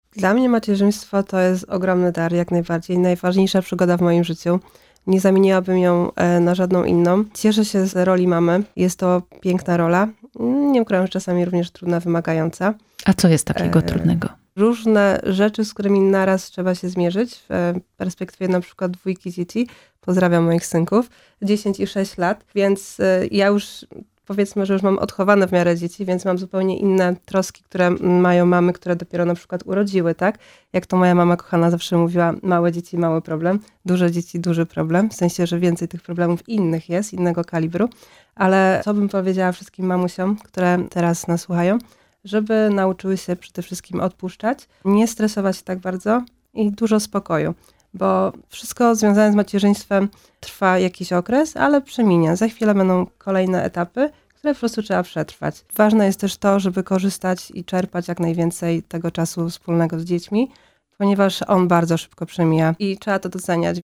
W studiu: